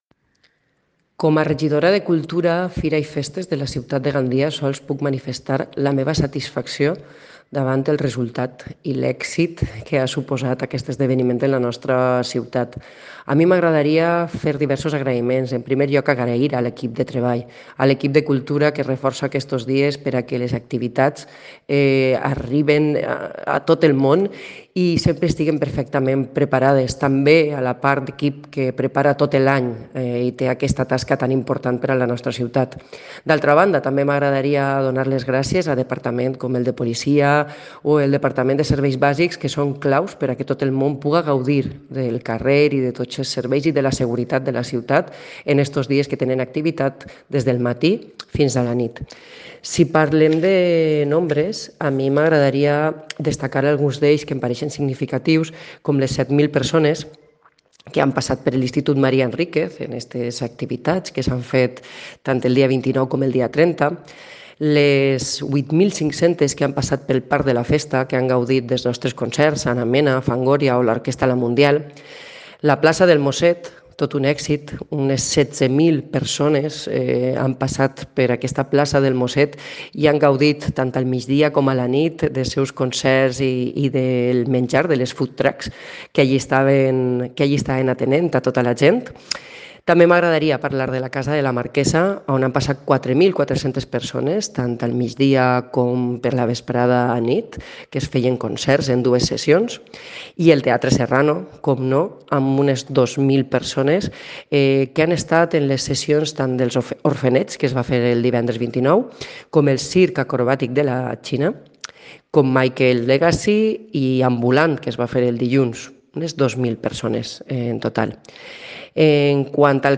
Escucha aquí a la tenienta de alcalde de Cultura,